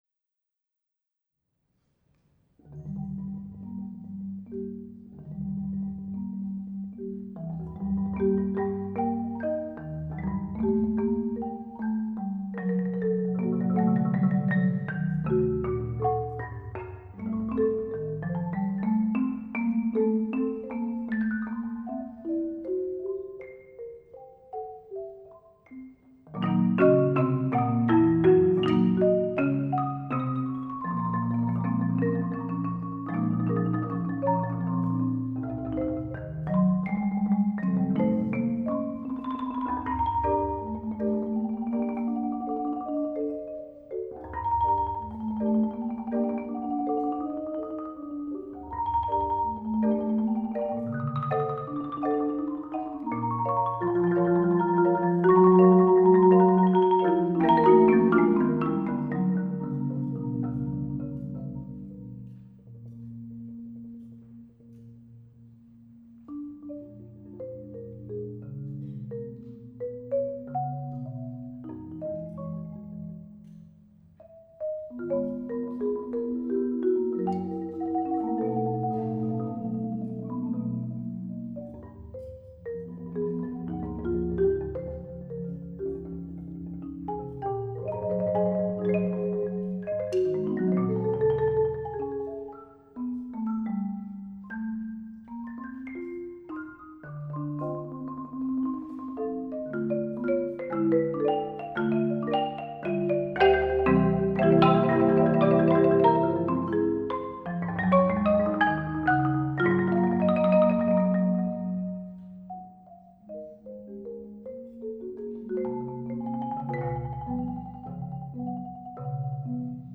Genre: Percussion Ensemble
# of Players: 4
Marimba 1 & 3 (4.3-octave)
Marimba 2 & 4 (5-octave)